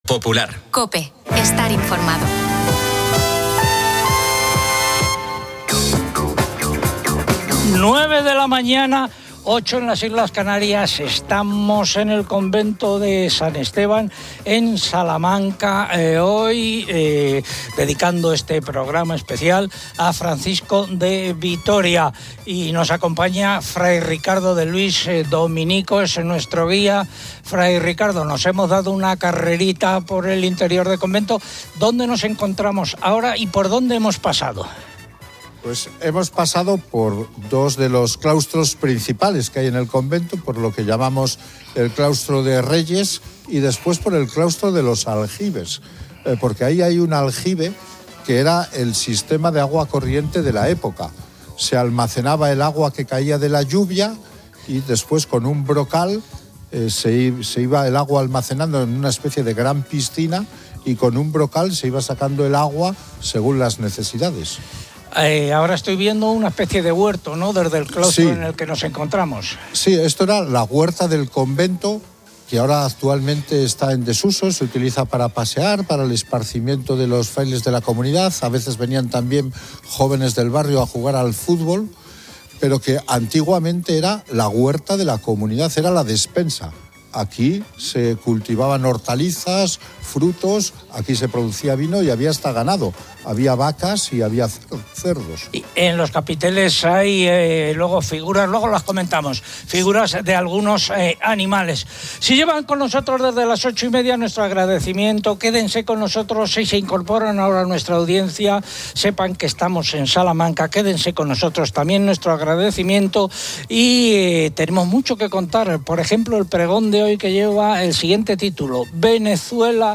El programa se transmite desde el Convento de San Esteban en Salamanca, conmemorando el quinto centenario de la llegada de Francisco de Vitoria,...